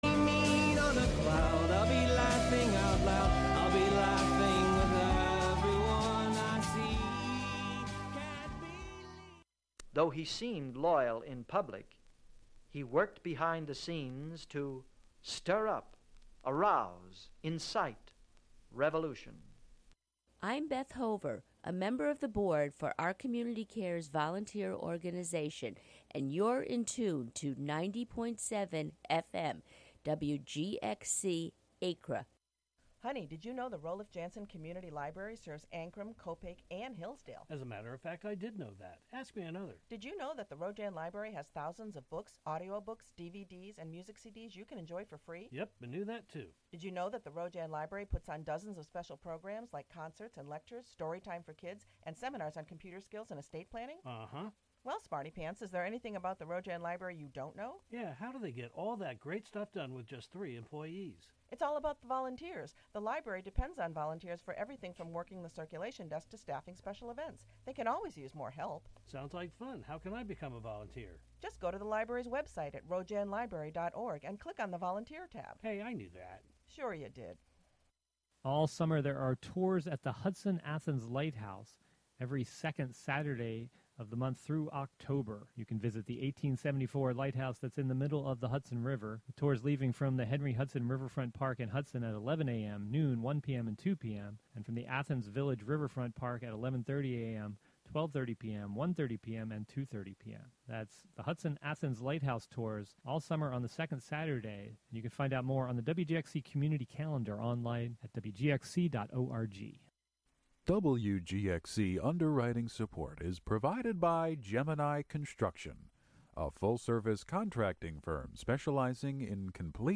Counting down ten new sounds, stories, or songs, "American Top 40"-style. Usually the top ten is recent songs, but sometimes there are theme countdowns.